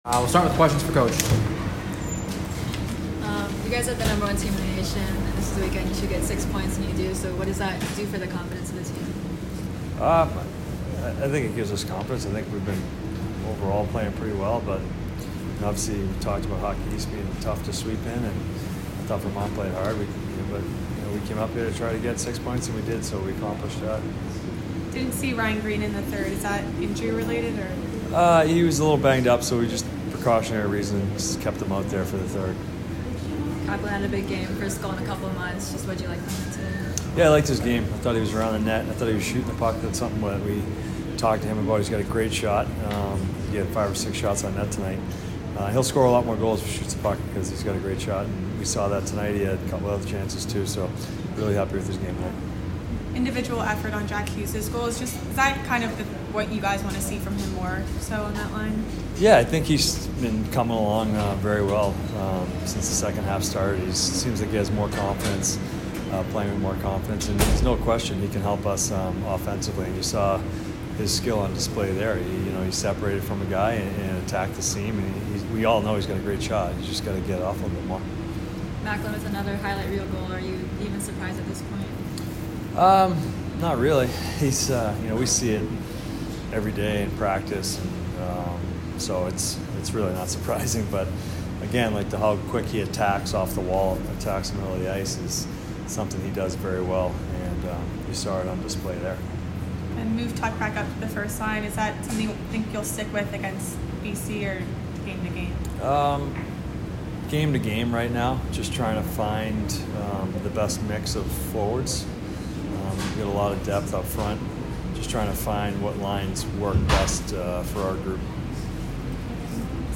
Men's Ice Hockey / Vermont Postgame Interview (1-20-24)